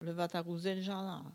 Localisation Bois-de-Céné
Catégorie Locution